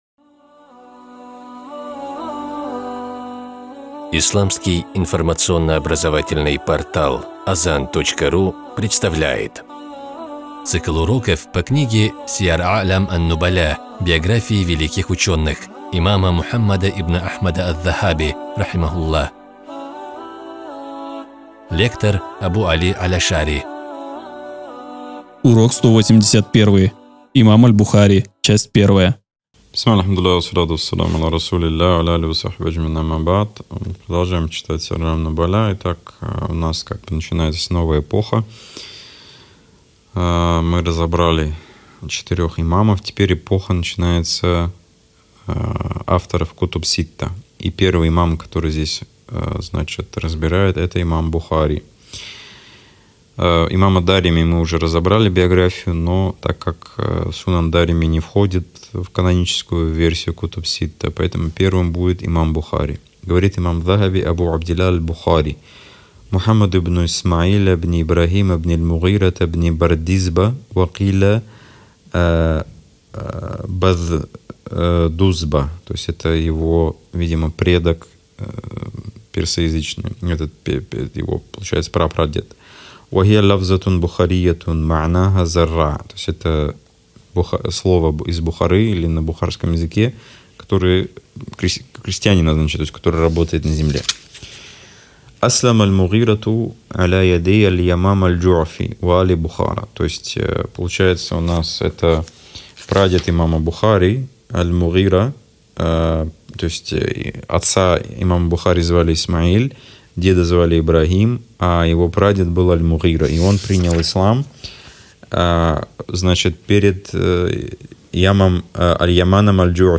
Цикл уроков по книге великого имама Аз-Захаби «Сияр а’лям ан-нубаля».